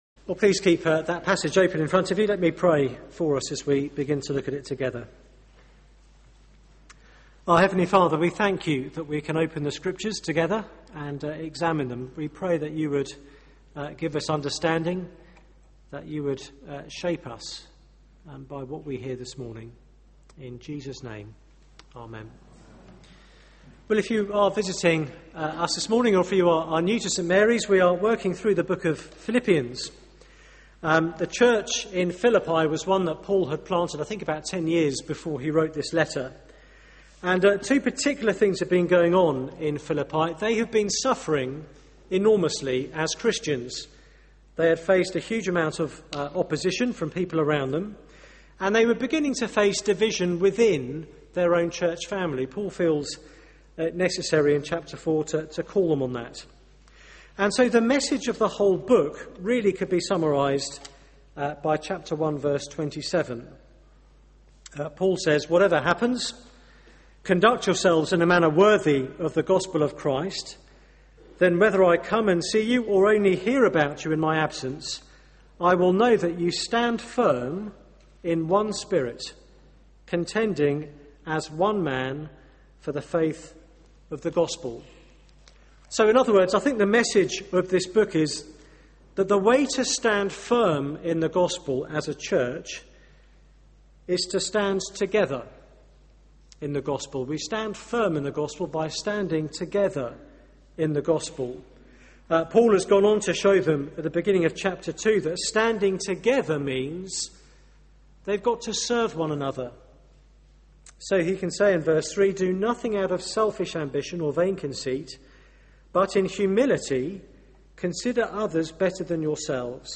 Shining for Christ Sermon